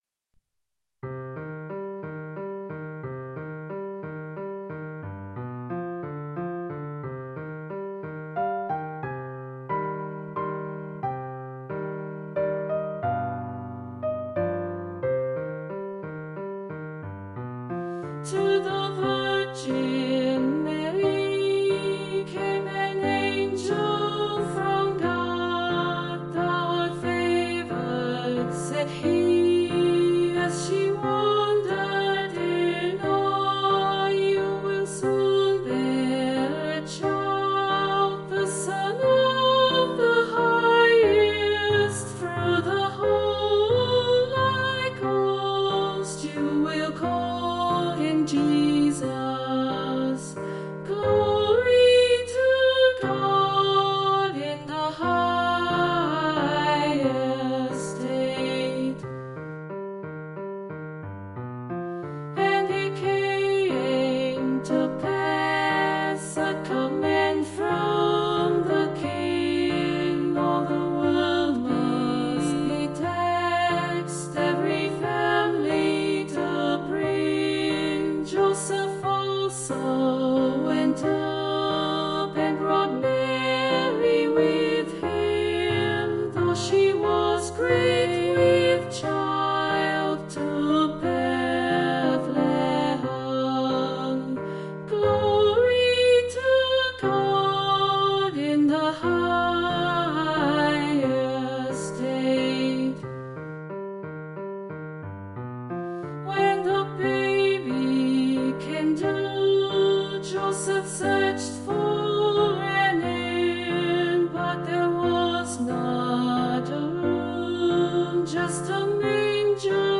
Voicing/Instrumentation: SA , SATB , TB , Duet
Vocal Solo Medium Voice/Low Voice Christmas